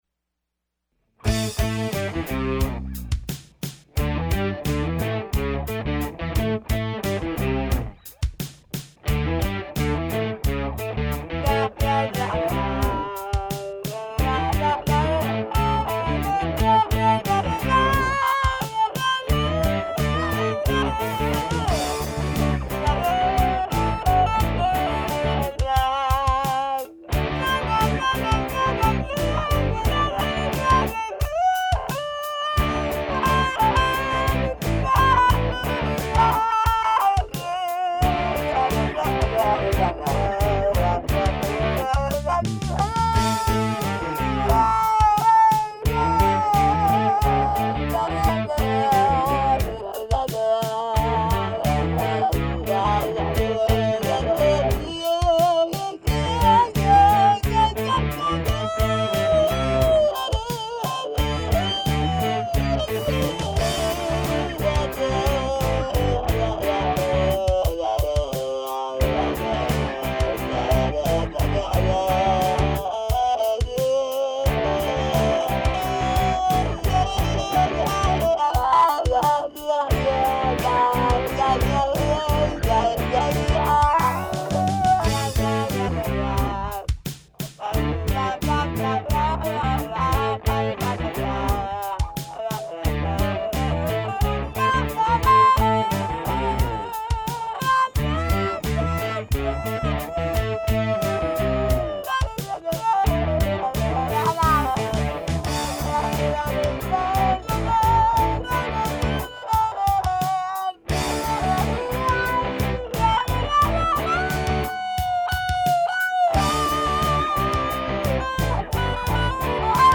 here's one of my sound experiments ..... just got to thinking about what it might sound like with buncha gits in unison.
The rest of the parts are 2nd and 3rd takes except the lead which is a first take.
By the end of the track I was getting some interesting sounds outta the talkbox and surely could have done something better but I was done at that point.
I mixed in my studio where it sounds pretty big on my good system which usually translates well to my 'puter speakers but this one seems to have the kick overloading my speakers a bit.
Attachments wah1.mp3 wah1.mp3 6.2 MB · Views: 61